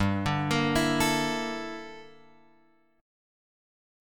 Gm6add9 chord {3 5 x 3 5 5} chord